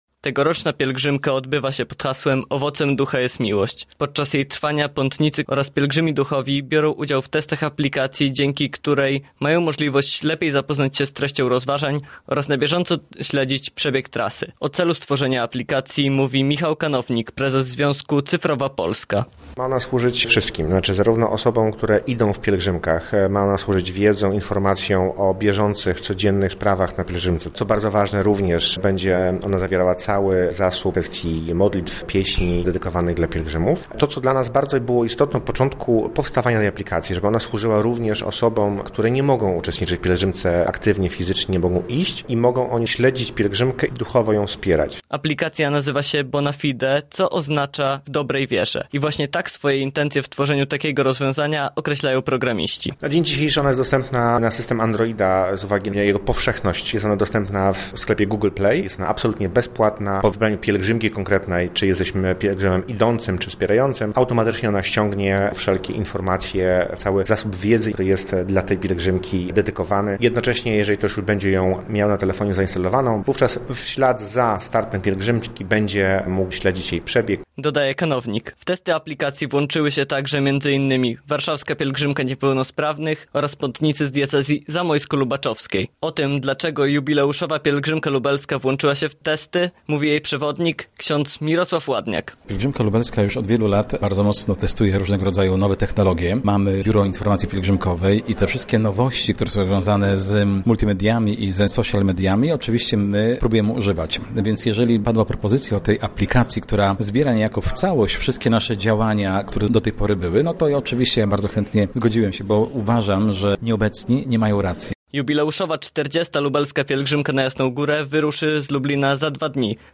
W tym roku w rozważaniu rekolekcji w drodze pomoże specjalna aplikacja. O szczegółach jej działania dowiedział się nasz reporter: